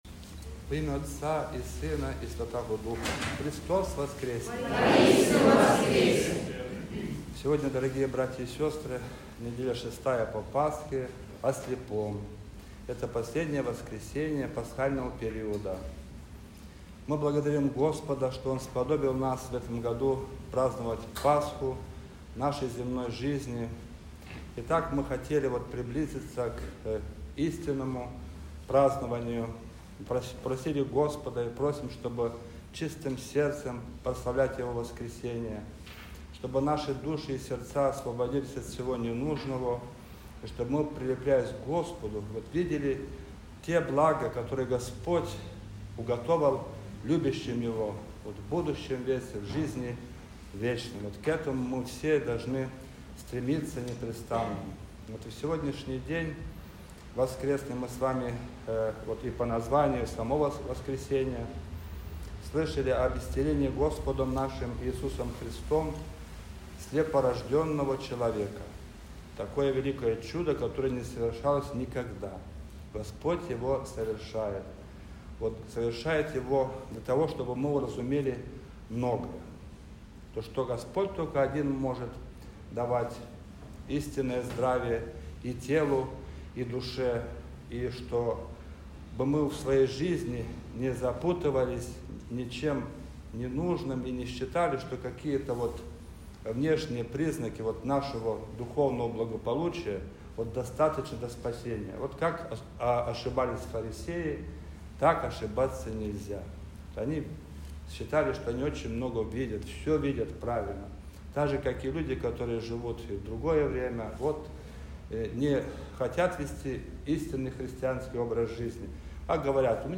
Неделя-6-я-по-Пасхе-о-слепом.mp3